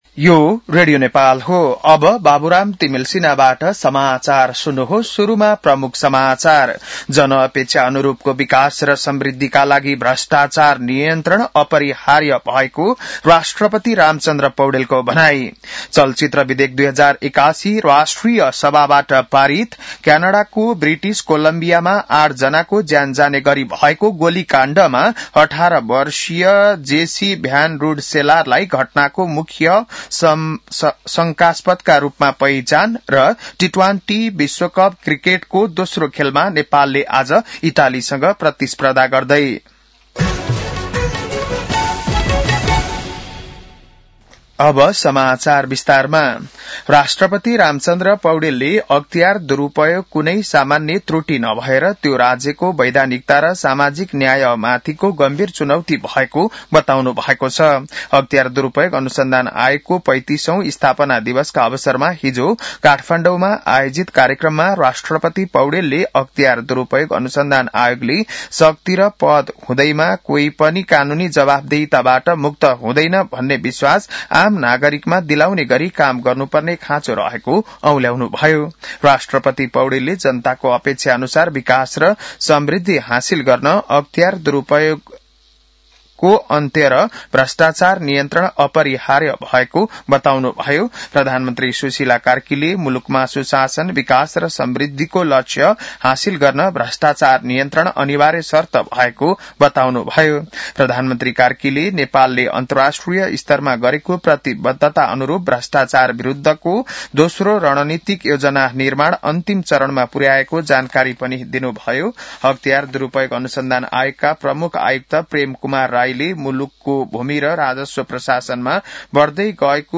बिहान ९ बजेको नेपाली समाचार : २९ माघ , २०८२